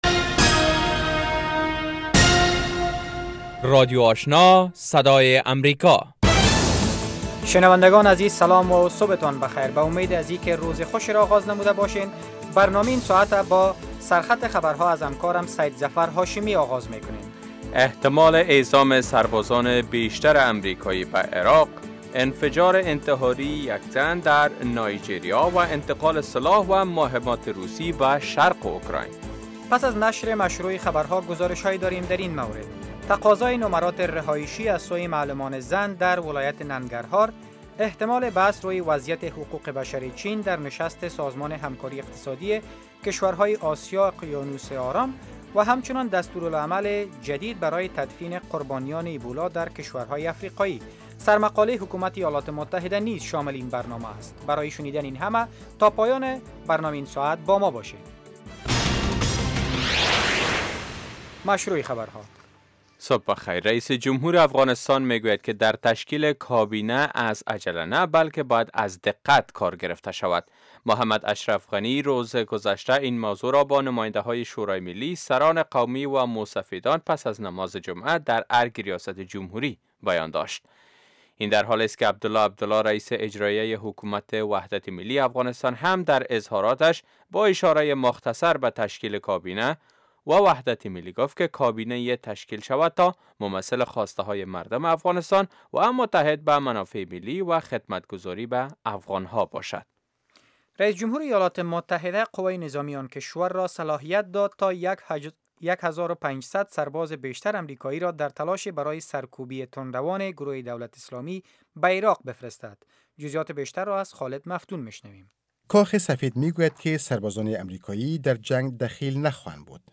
اولین برنامه خبری صبح